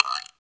PRC GUIRO 2.wav